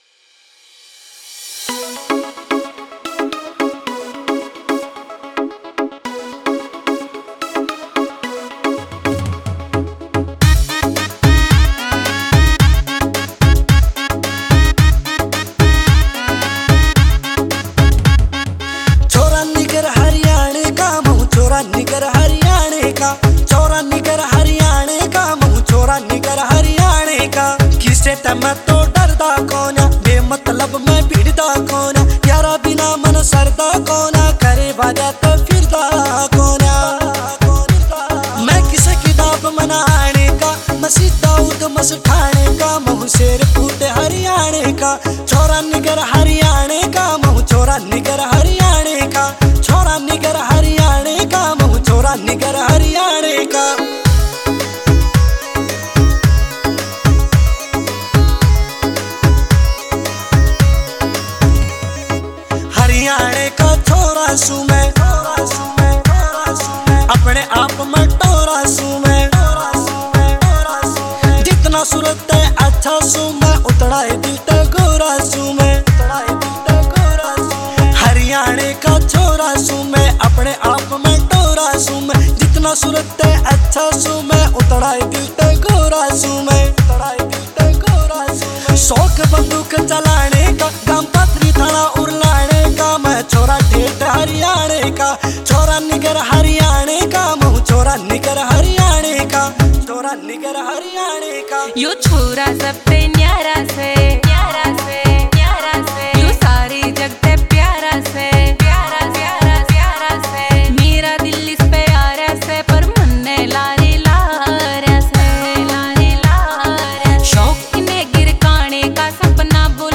Haryanvi Mp3 Songs